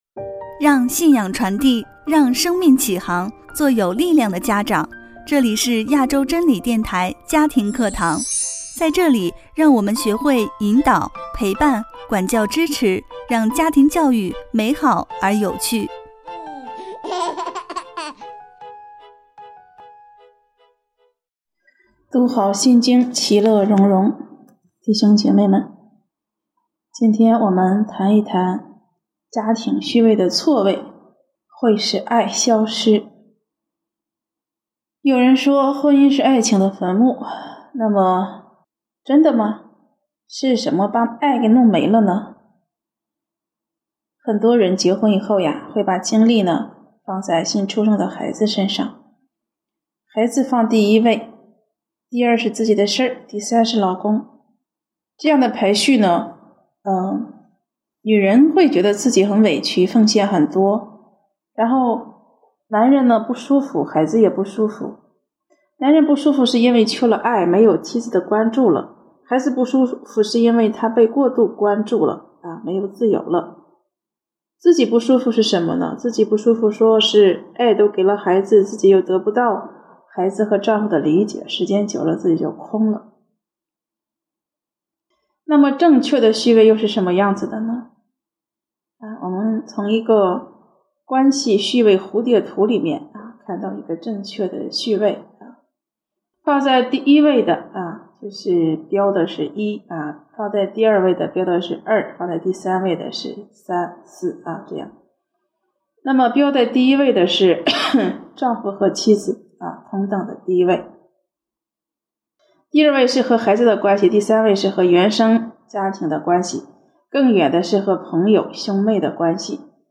第三十四讲 家庭序位的错位是爱消失的原因